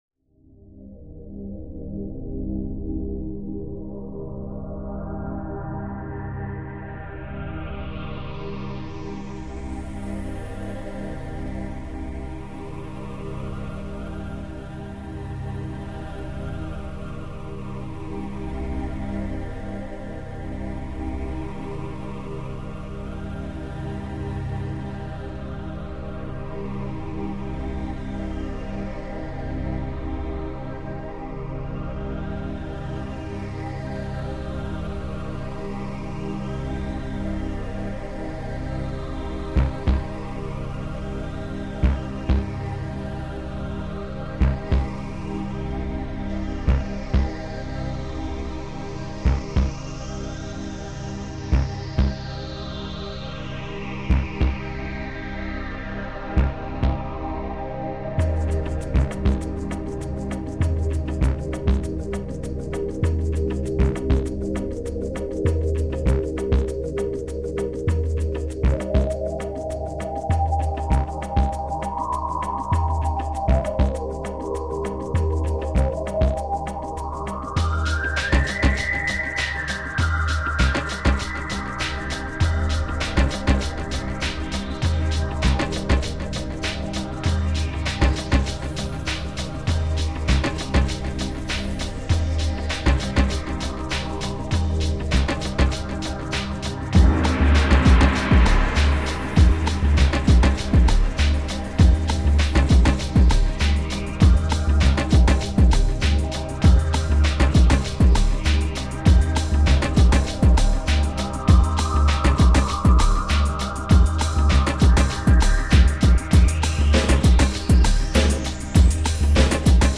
fun bumpy-bump music